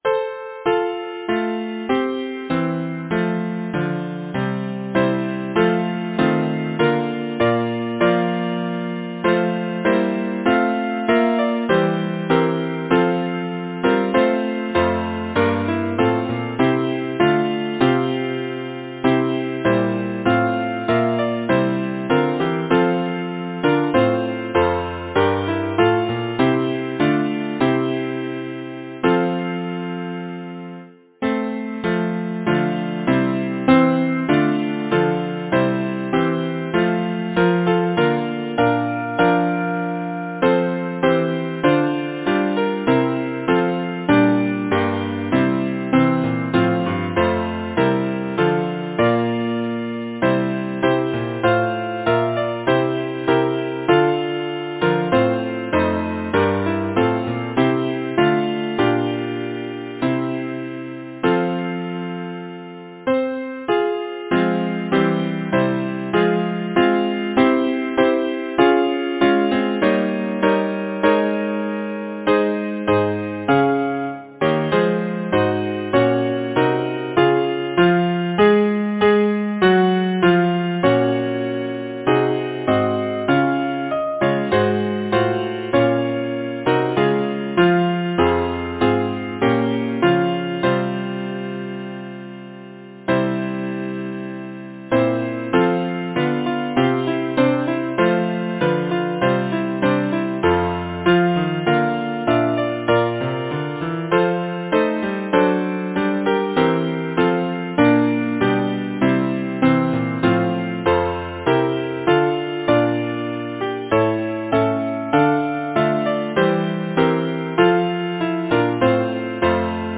Title: Low dies the day Composer: Henry Charles Banister Lyricist: Elliot Stock Number of voices: 4vv Voicing: SATB Genre: Secular, Partsong
Language: English Instruments: A cappella